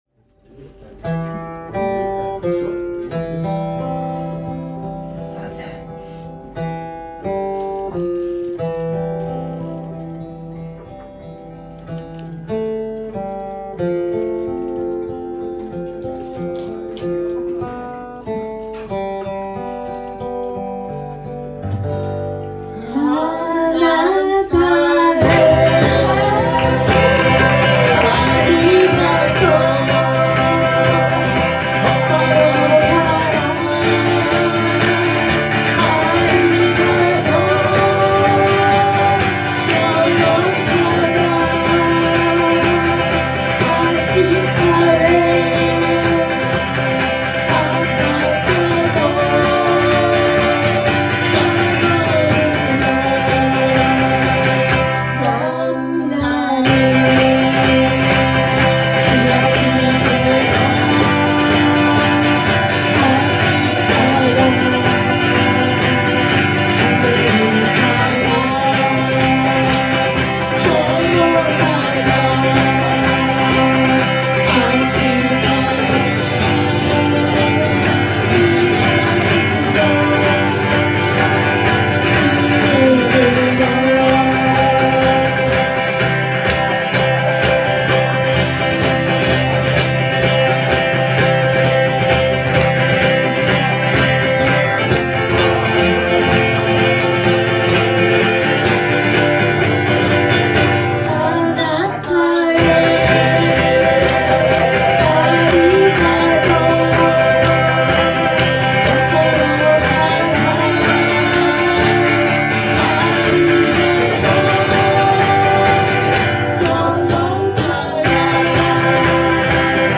緩やかなイントロに続く元気な歌い出しで、明るい展開になっています。
ライブ音源